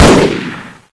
hksShoot2.ogg